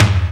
prcTTE44005tom.wav